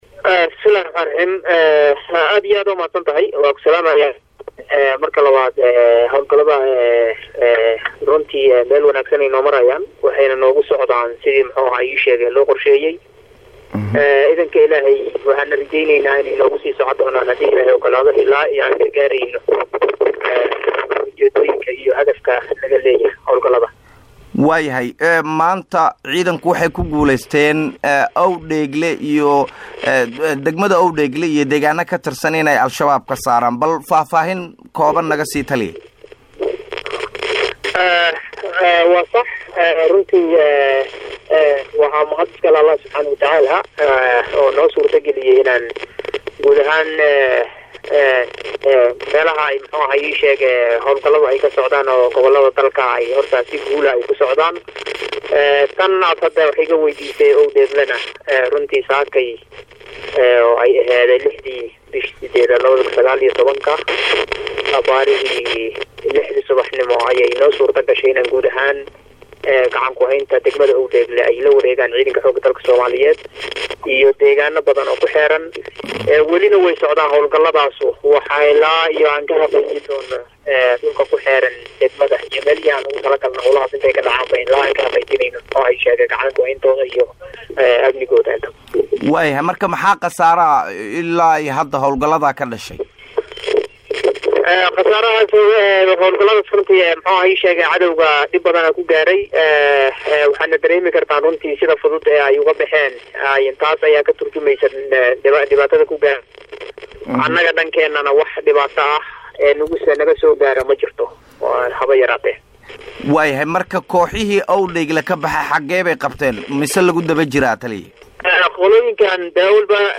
Halkaan Hoose ka Dhageyso Wareysiga
WAREYSI-TALIYAHA-CIIDANKA-LUGTA-EE-XOOGGA-DALKA-SOOMAALIYEED-GEN-ODOWAA-YUUSUF-RAAGE-1-2.mp3